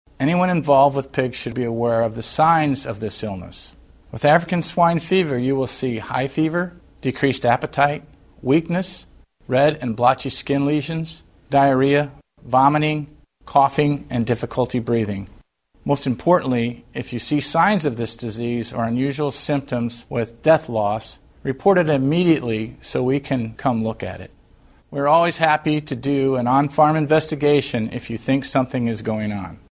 USDA Chief Veterinarian Dr. Jack Shere lists some of the symptoms pigs can show when infected with ASF.